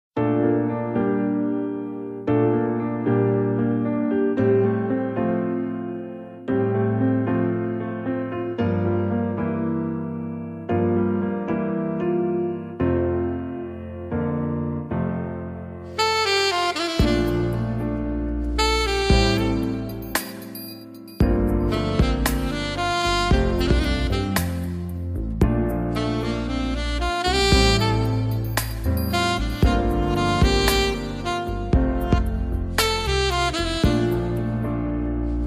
JAPANESE-SMOOTH-SOOTHING-JAZZ.mp3